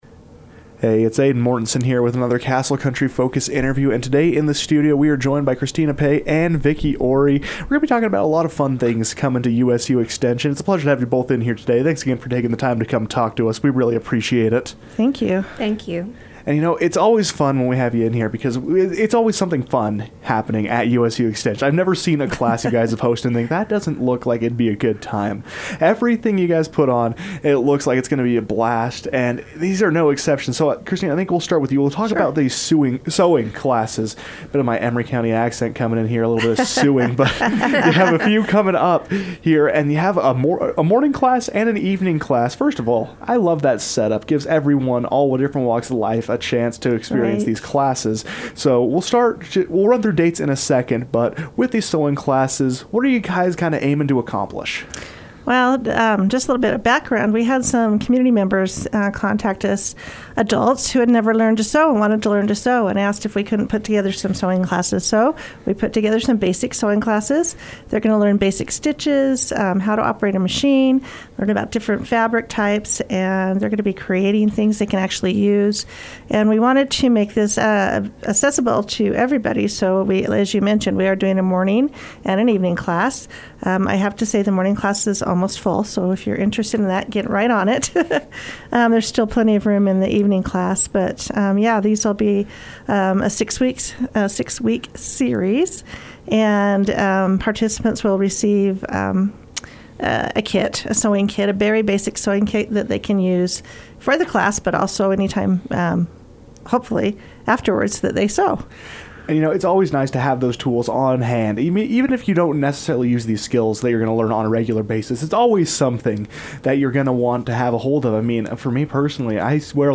Connect with the community through USU Extension's 'Creating Connections' series of events | KOAl - Price,UT